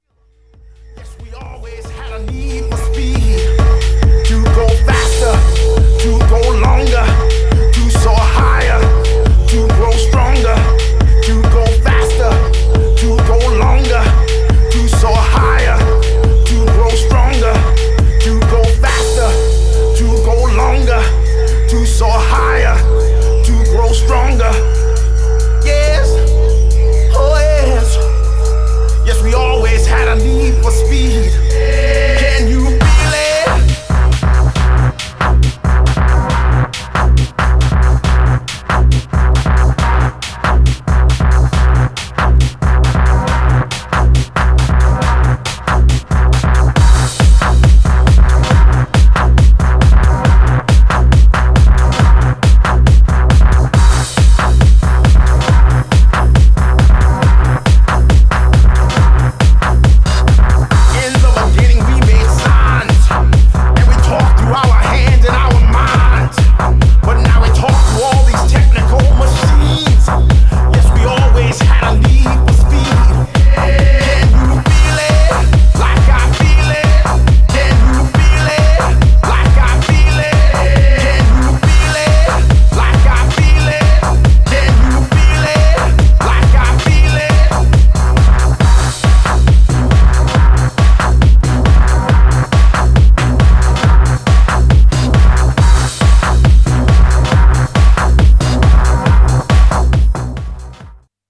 Styl: Progressive, House, Techno, Breaks/Breakbeat